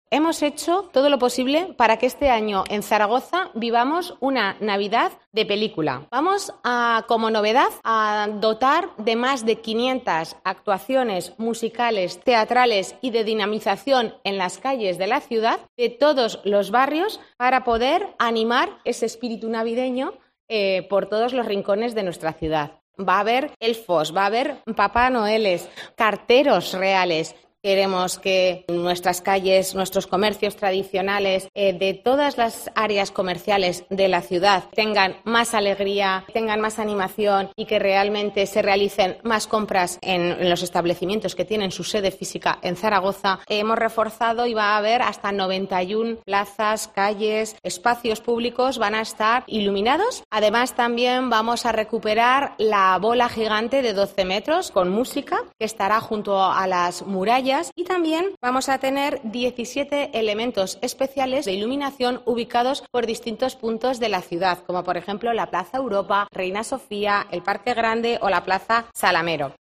La alcaldesa de Zaragoza, Natalia Chueca, explica algunos detalles de cómo será esta próxima Navidad